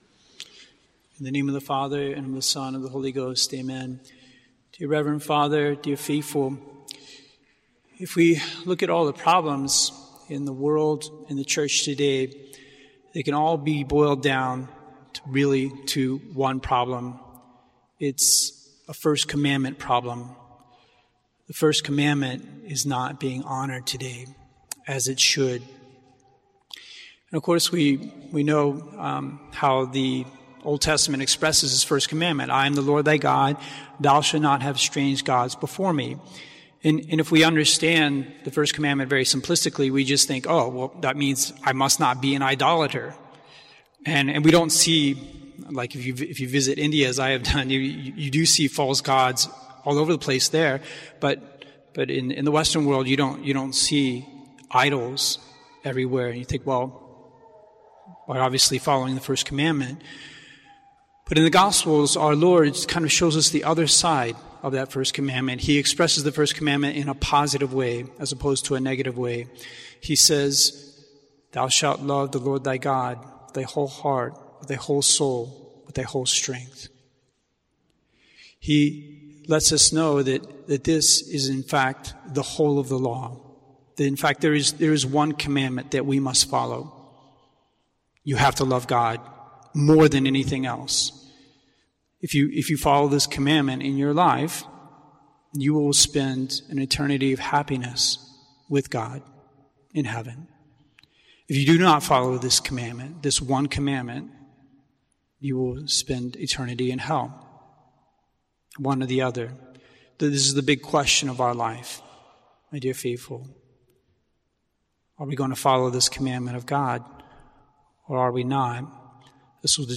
The World and the Church's First Commandment Problem, Sermon